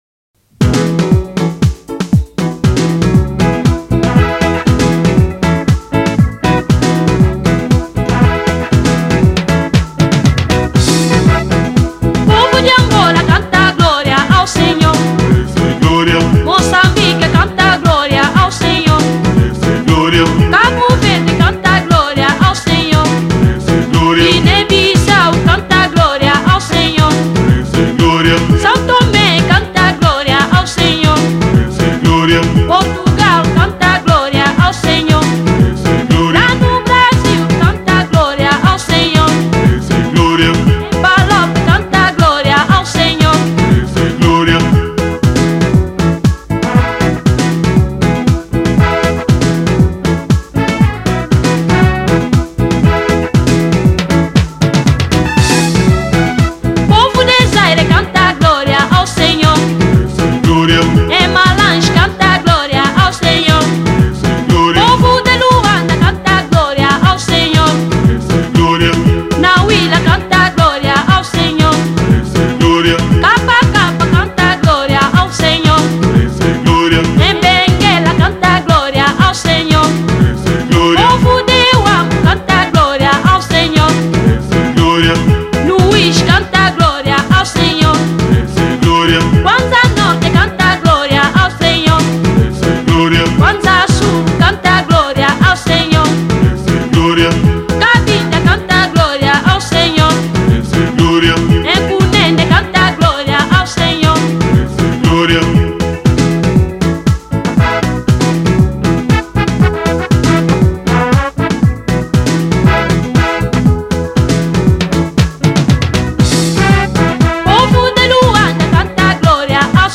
Gospel 2003